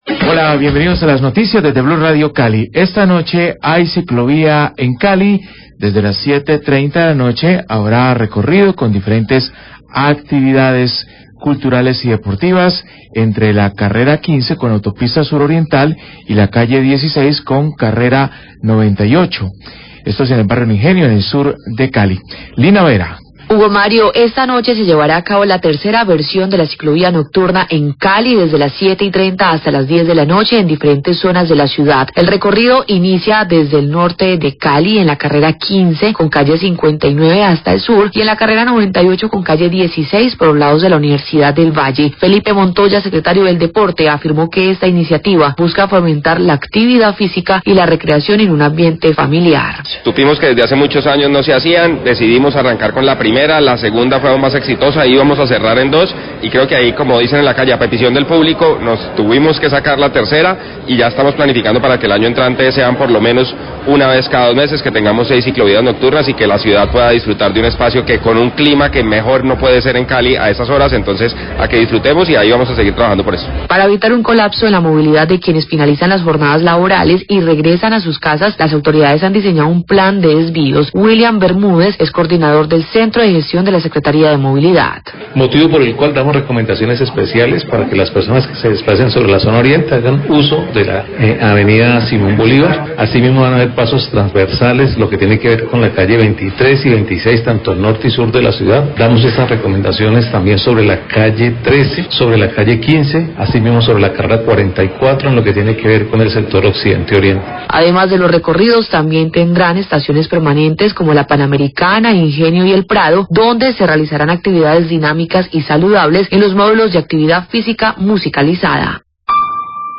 Srio. Deporte Cali y funcionario Sria. Movilidad hablan de realización ciclovia nocturna
Radio